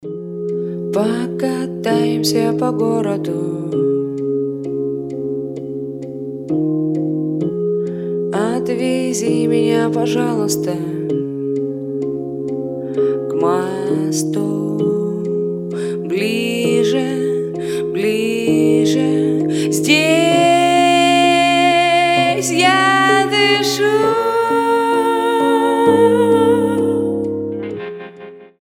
• Качество: 320, Stereo
душевные
атмосферные
медленные
расслабляющие
тиканье часов